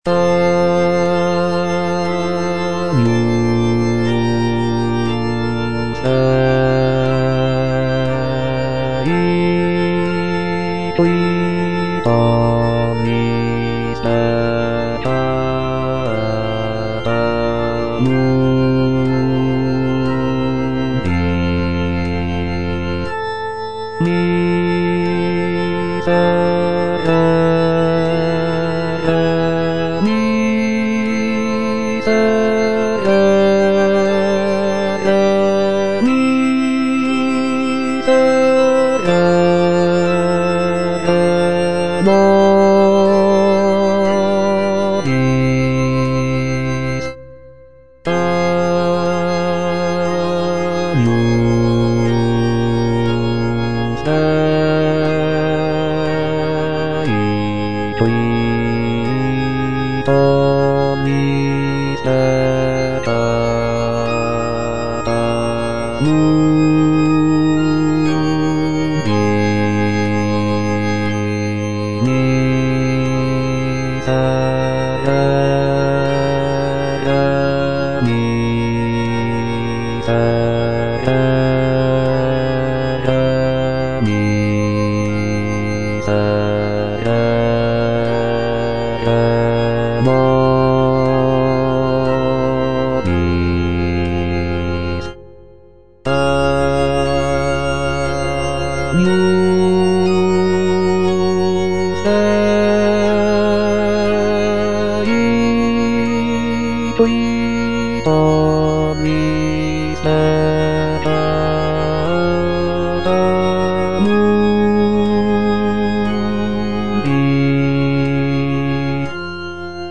T. DUBOIS - MESSE IN F Agnus Dei - Bass (Voice with metronome) Ads stop: auto-stop